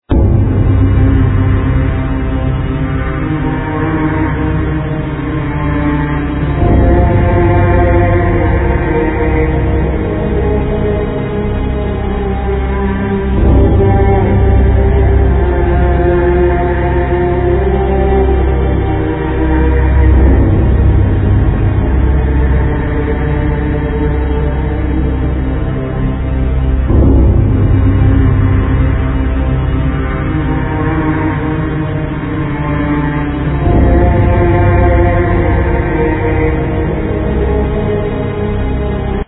Bowed Cymbal, Trombone